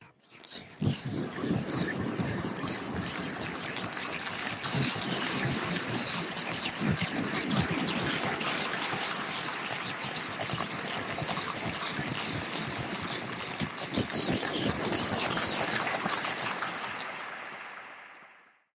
Звуки ада
На этой странице собраны пугающие звуки ада: от далеких воплей до навязчивого скрежета.